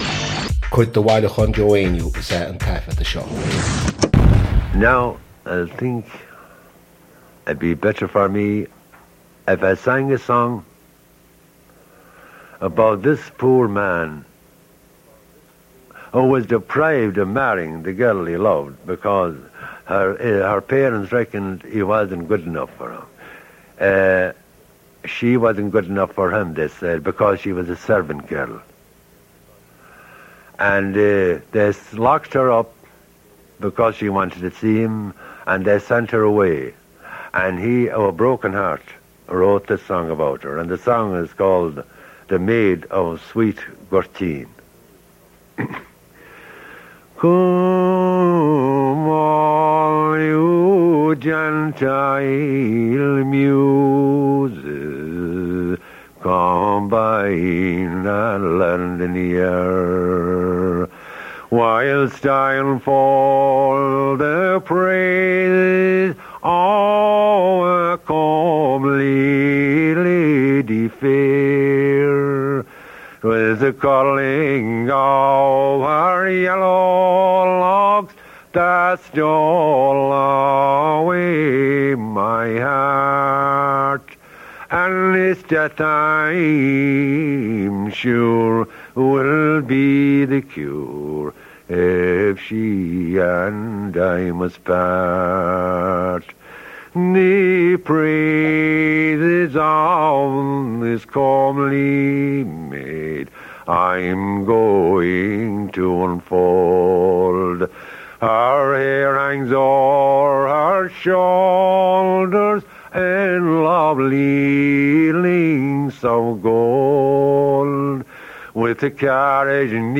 • Catagóir (Category): Song.
• Ainm an té a thug (Name of Informant): Joe Heaney.
• Suíomh an taifeadta (Recording Location): probably at the University of Pennsylvania, United States of America.
• Ocáid an taifeadta (Recording Occasion): day class.